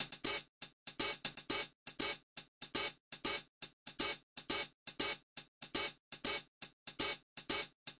kick hat tinny snare 120bpm " hihat loop 120bpm0102
描述：hihat循环120bpm
Tag: 的PERC 循环 尖细 120BPM 打击乐器 量化 节奏 打击乐器环 鼓循环 常规